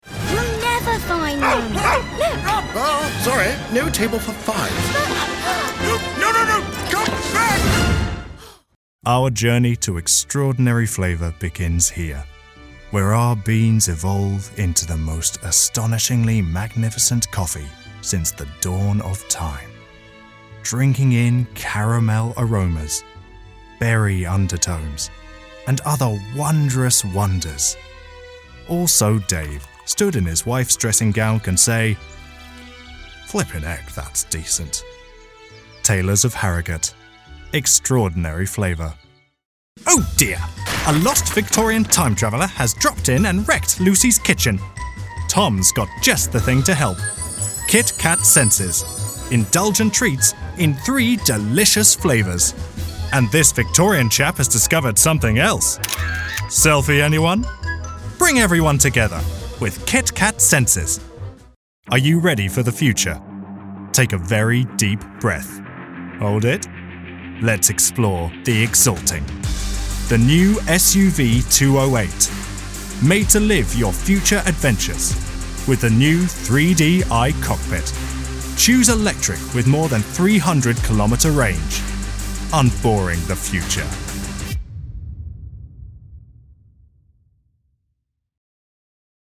Deep, Classic, Genuine